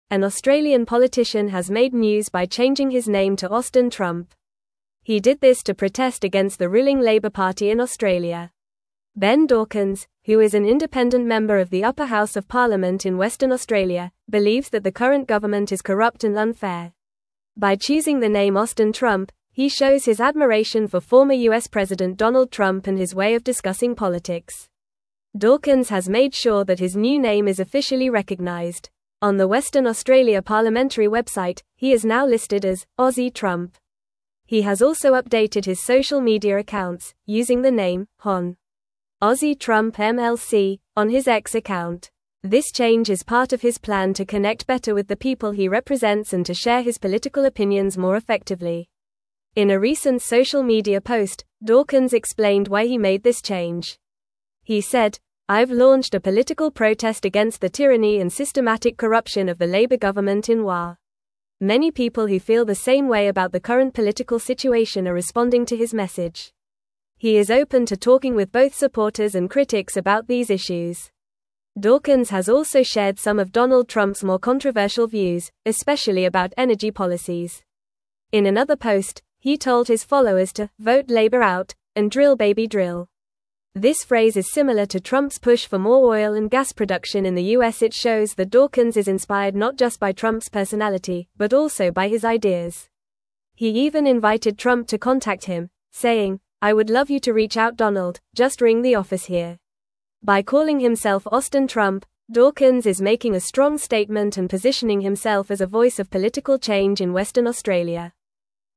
Fast
English-Newsroom-Upper-Intermediate-FAST-Reading-Australian-Politician-Changes-Name-to-Austin-Trump.mp3